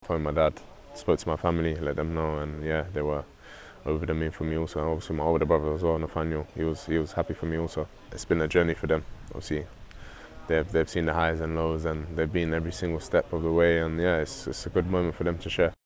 Chelsea defender Trevoh Chalobah shares his thoughts on being called up to the England squad.